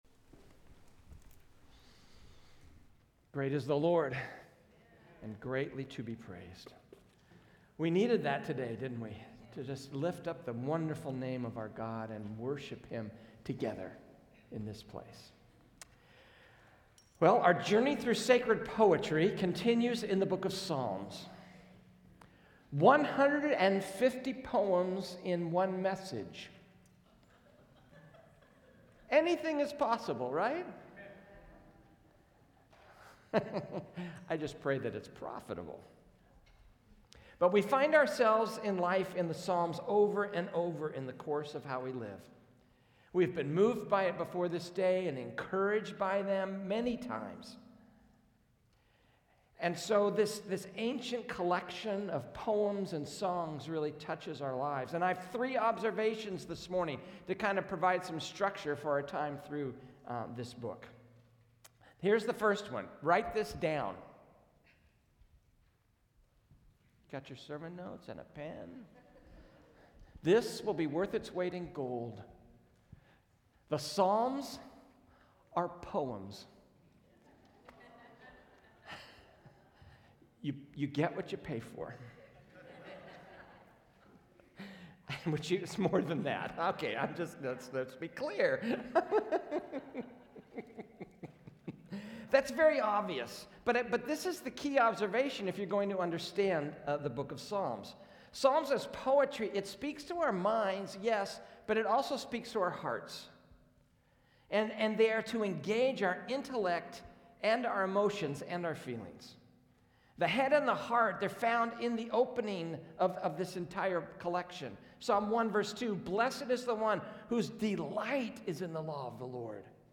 A message from the series "Timeless."